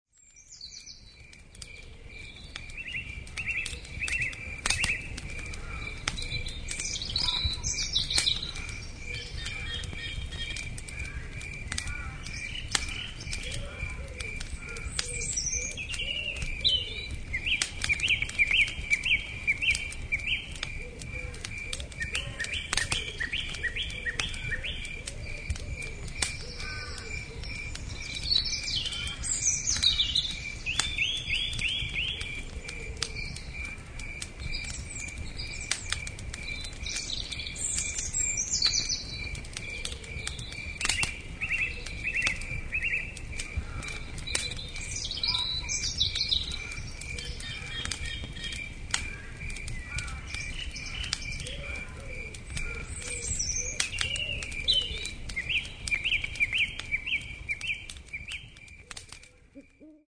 Category: Animals/Nature   Right: Personal